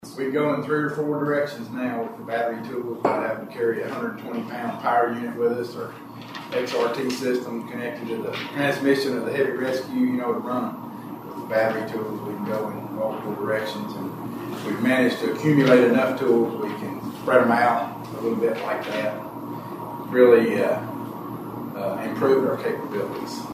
Magistrates in Caldwell County learned about significant upgrades to local emergency equipment—specifically, battery-powered rescue tools—during this week’s Fiscal Court meeting.